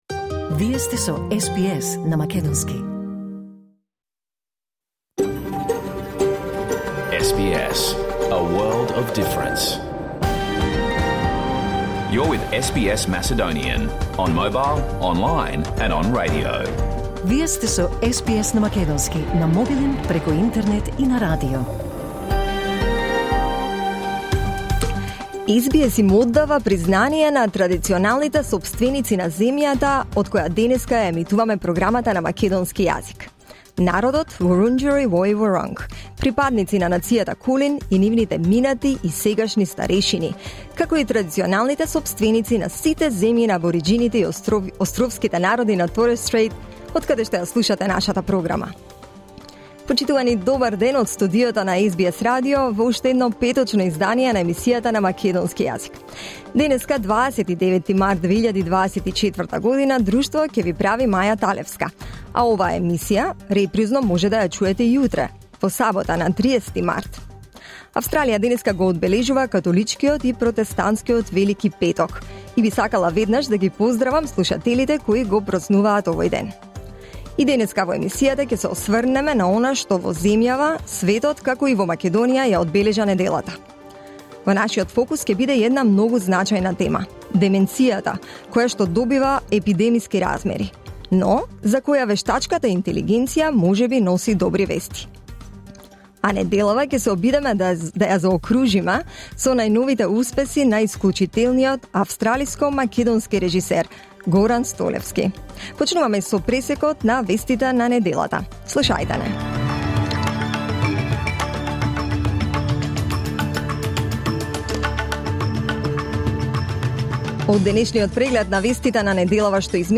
SBS Macedonian Program Live on Air 29 March 2024